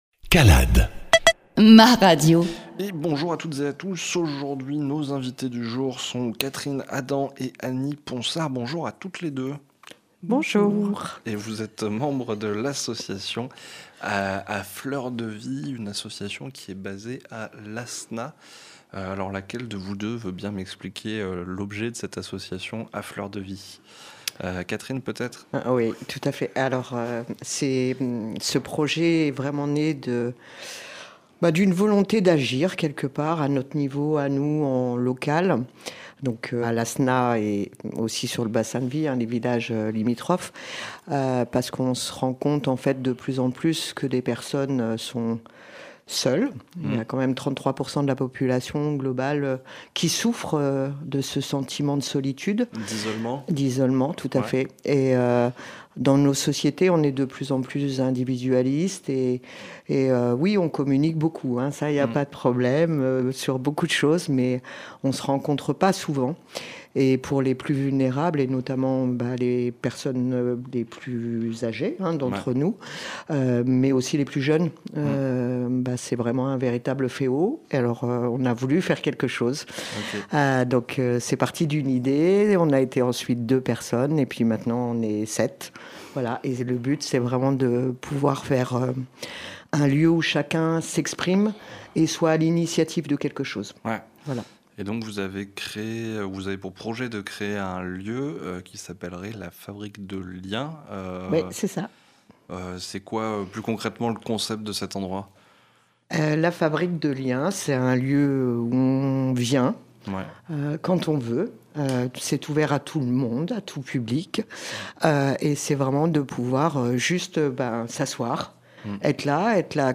Interview Calade – 070425 A Fleurs de Vie